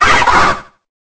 Cri_0845_EB.ogg